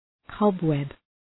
Προφορά
{‘kɒbweb}